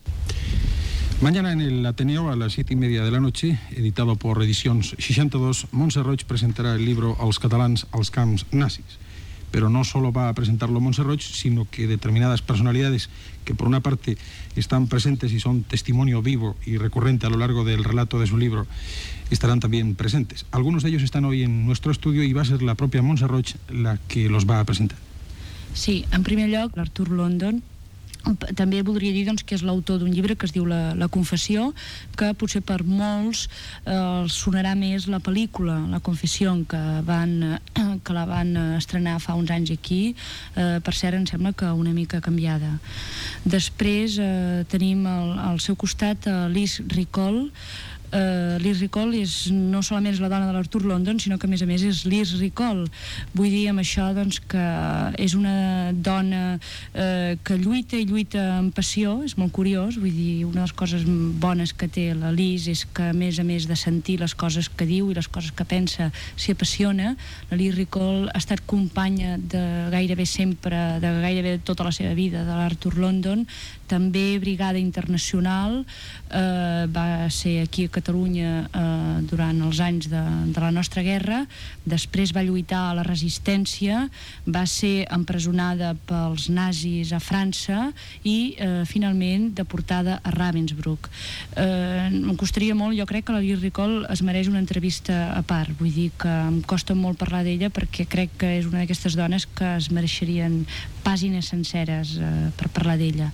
L'escriptora Montserrat Roig presenta el llibre "Els catalans als camps nazis" i explica l'acte de presentació a l'Ateneu Barcelonès
Informatiu